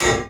axe_metal.wav